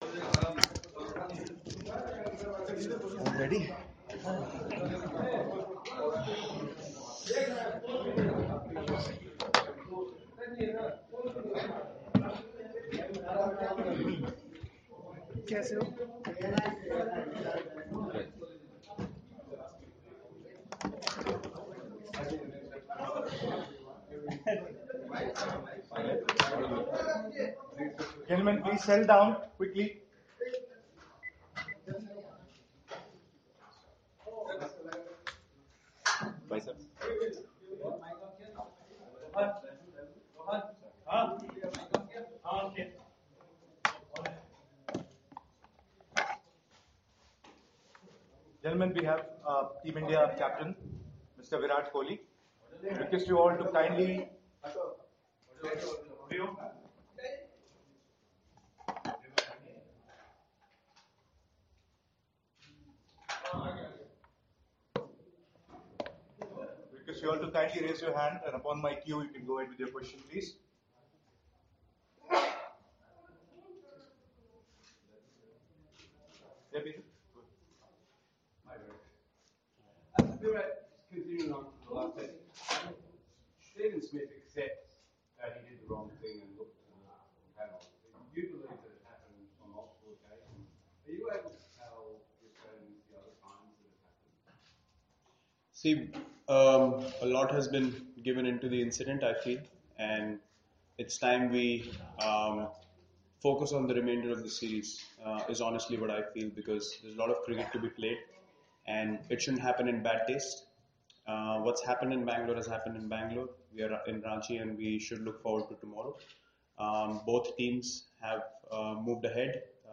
LISTEN: Skipper Virat Kohli speaks ahead of the Ranchi Test